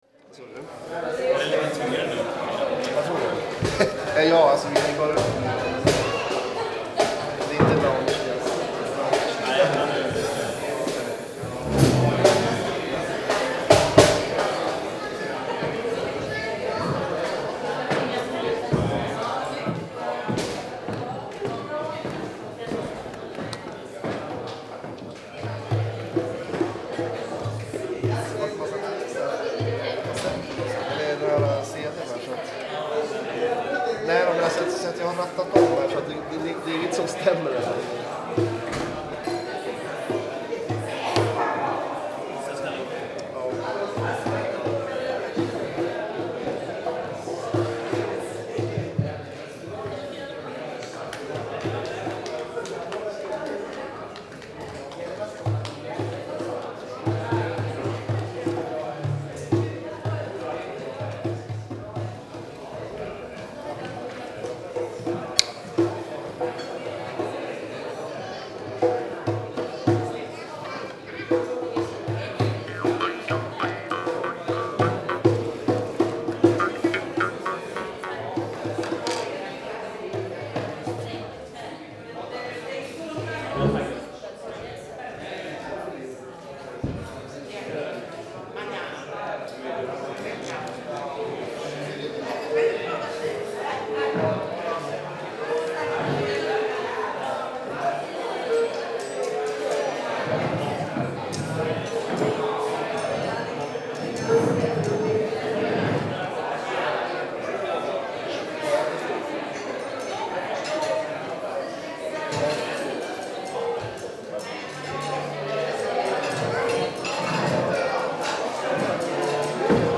Before a gig at Folkets Hus in Bj